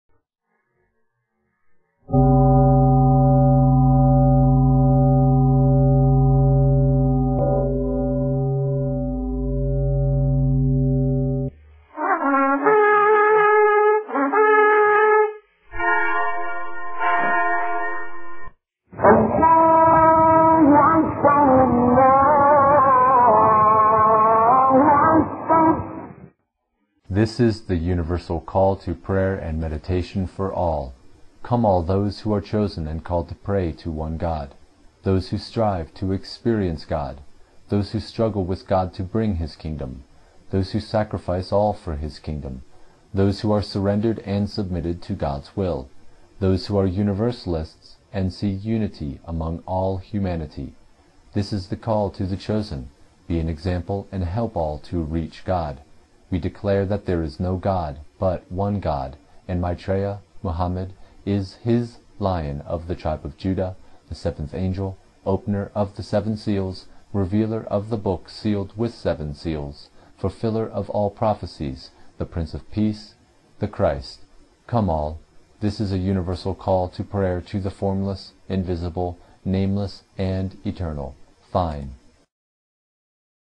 Now we have decided to make this call more universal and use an audio which combines sounds from the four major religions on earth (Mystical Paths, Judaism, Christianity and Islam) to Call to Prayer.
2), we read the words we now have in the Mission as the "Universal Call to Prayer."